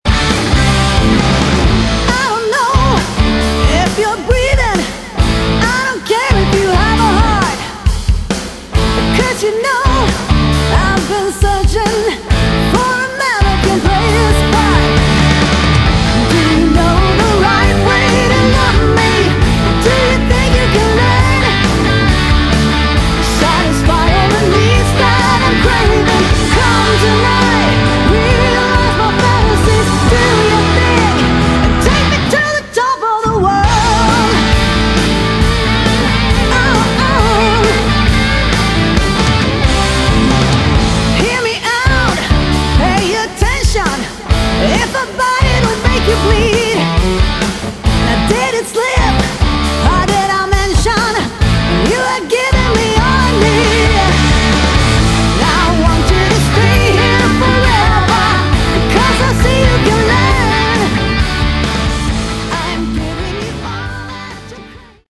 Category: Hard Rock
vocals
guitars
bass guitar
drums